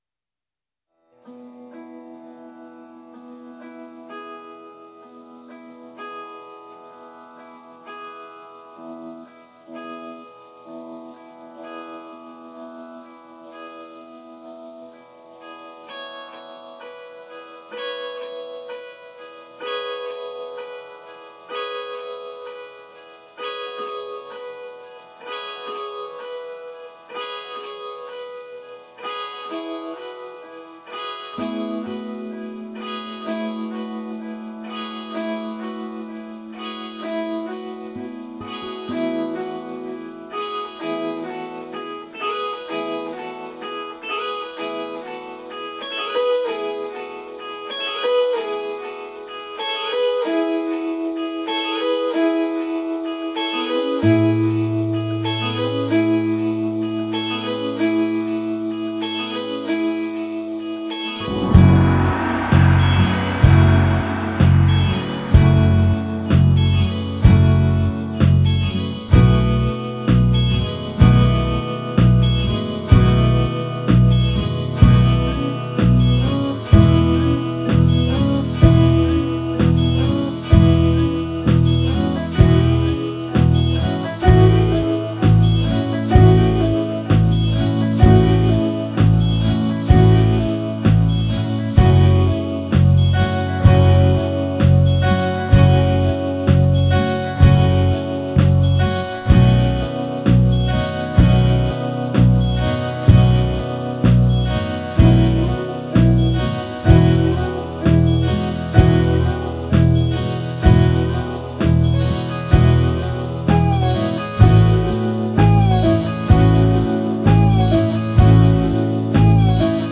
a softly looping instrumental